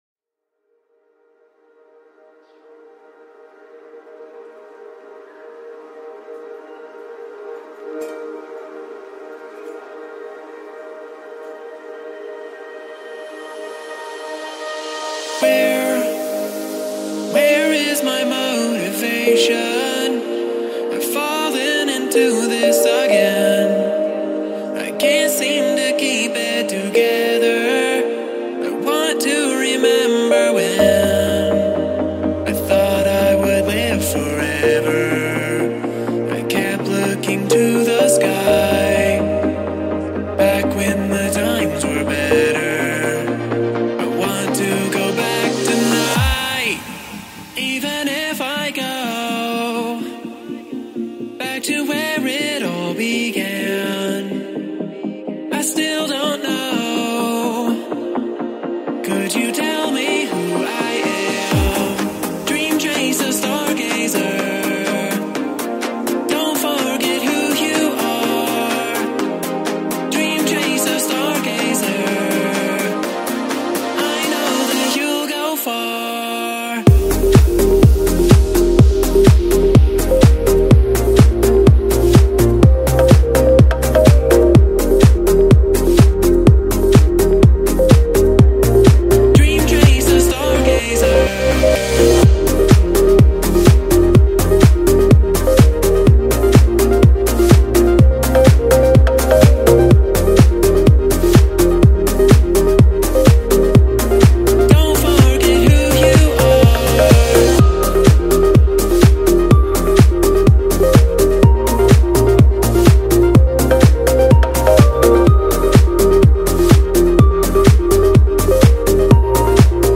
genre:remix